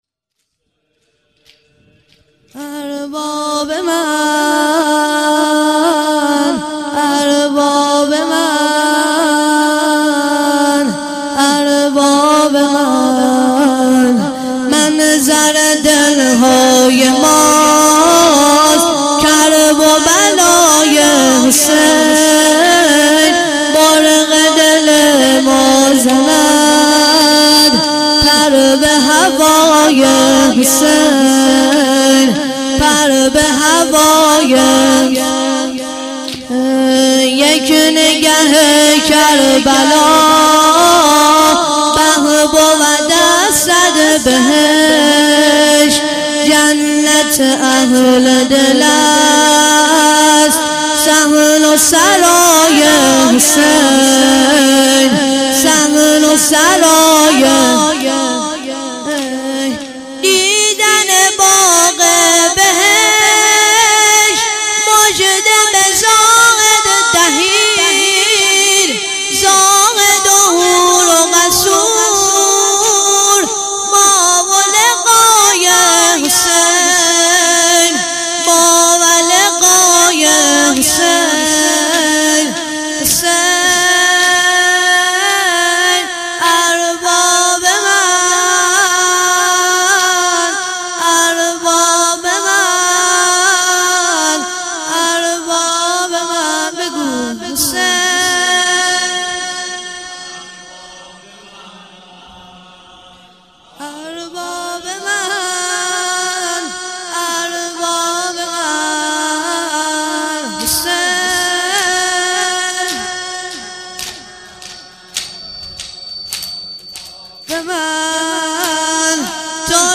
شهادت حضرت میثم تمار ره 1393 مداح